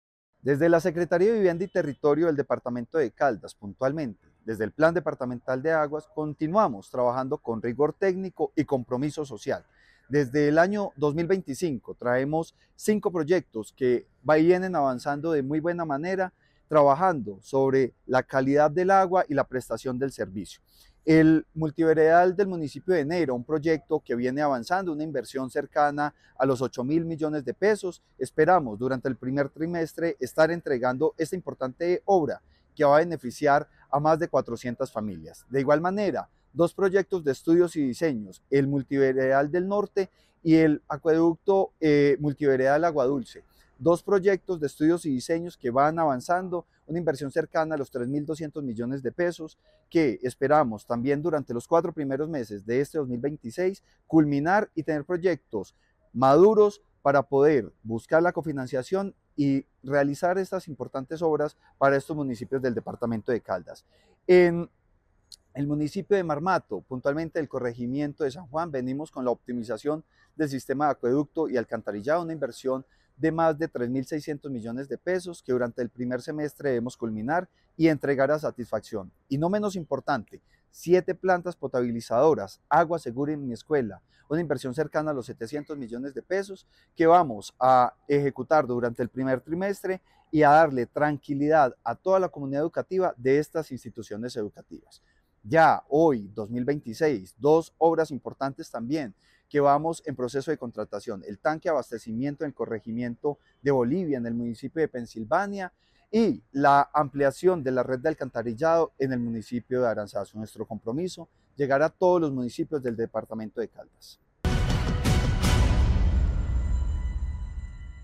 Secretario de Vivienda de Caldas, Francisco Vélez Quiroga.